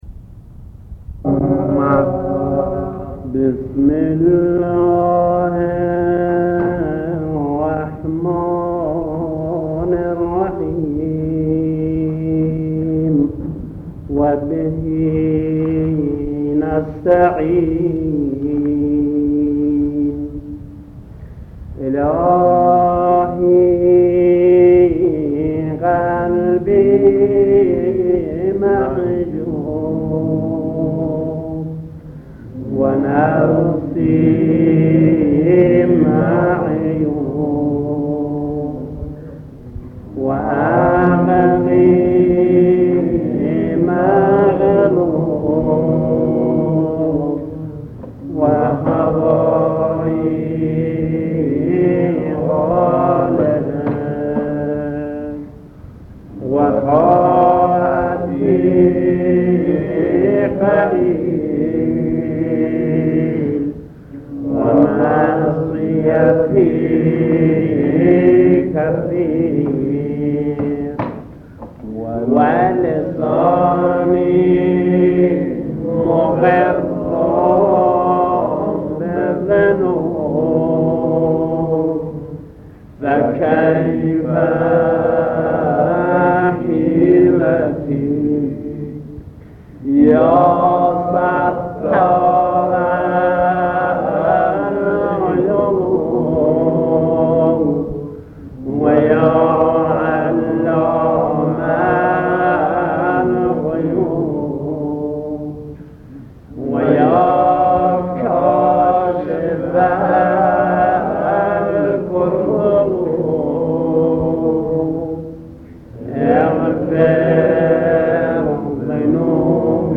قرائت دعای کمیل